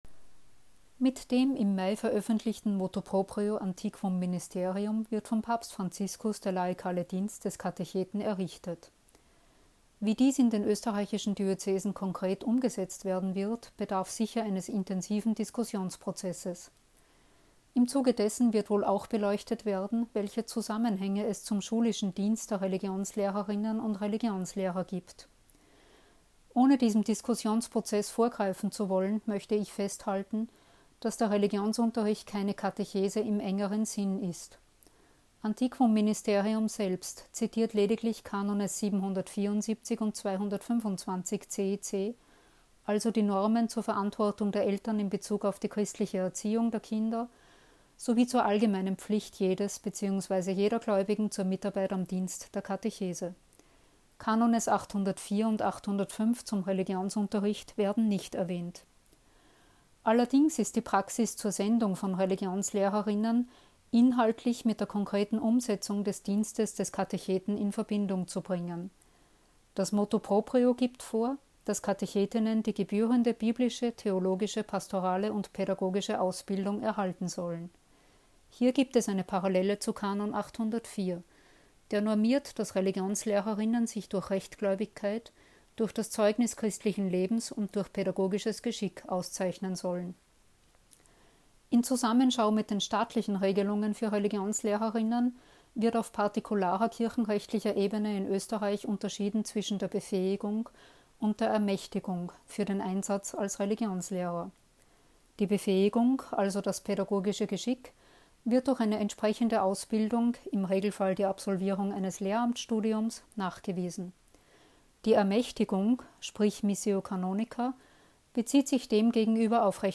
O-Ton: